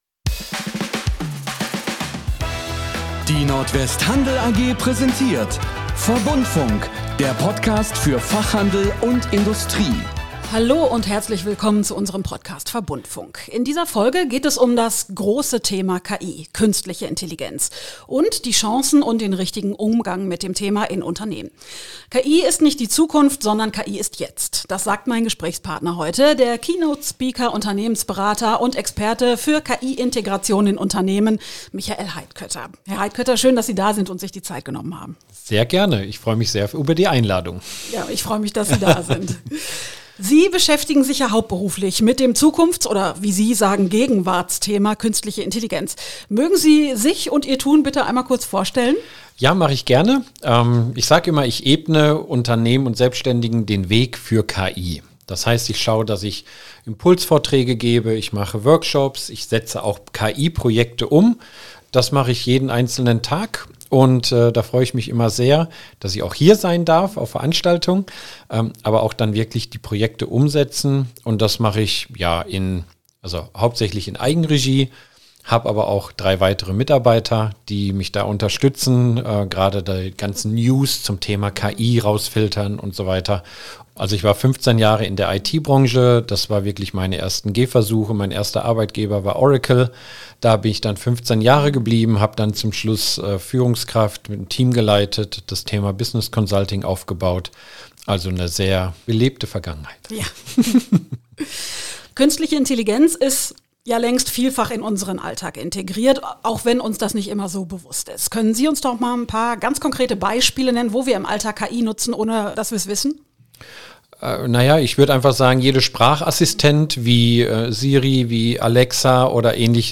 Er erzählt ganz pragmatisch über erste Schritte, hilfreiche Tools und den vielfältigen Nutzen.